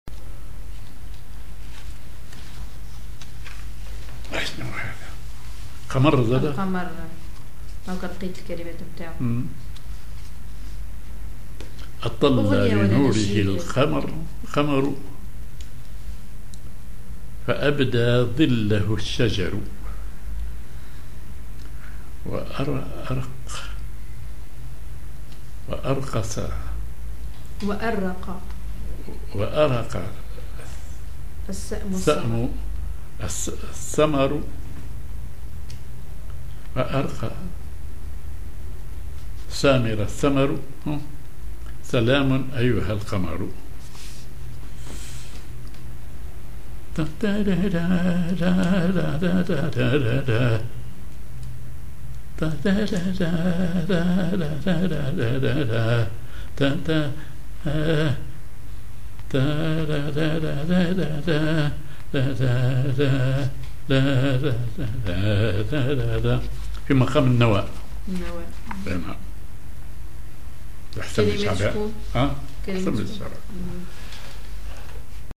Maqam ar نوا
Rhythm ar برول
genre نشيد